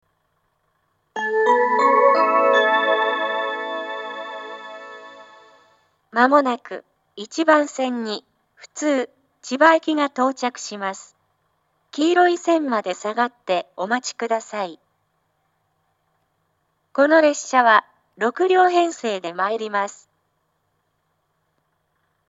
１番線接近放送 普通千葉行（６両）の放送です。